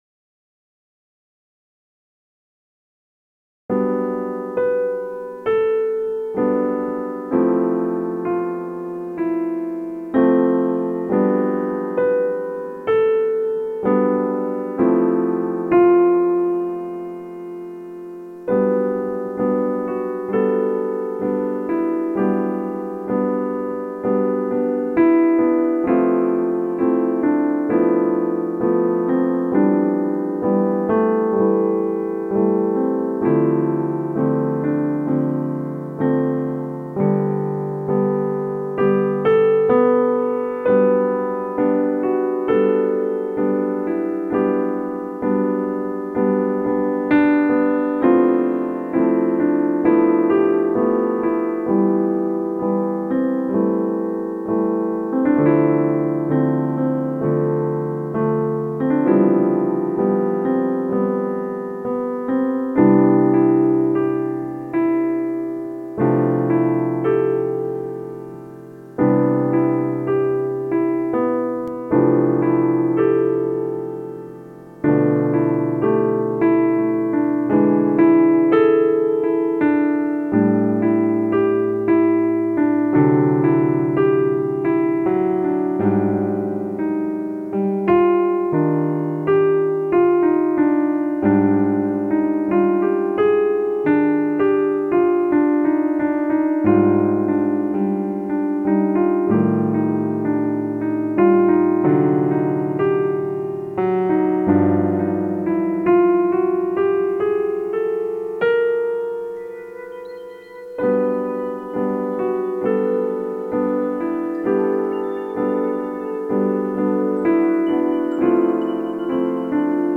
Christmas Melodic Piano,Pad & Orchestra